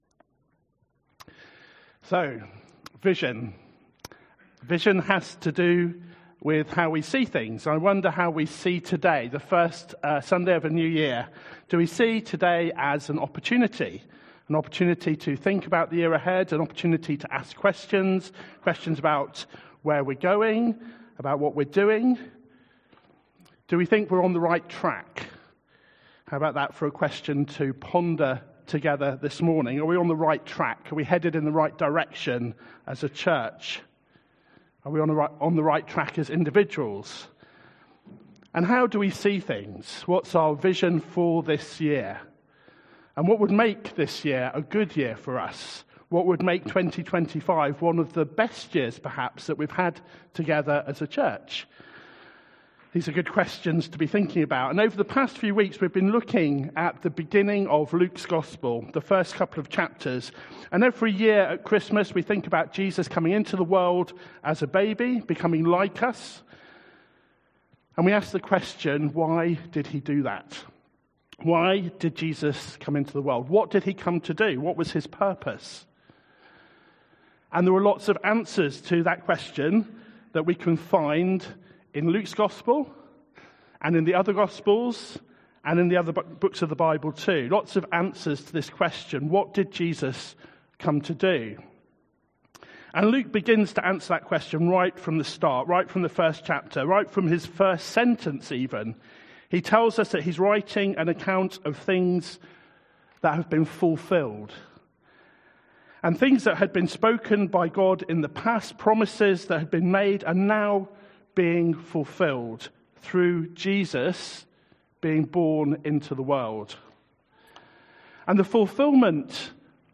Each Sunday as part of our gathering someone gives a talk from the Bible. This takes us back to the source of our faith and reminds us of the Jesus we follow.